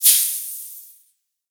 pressure_release01.wav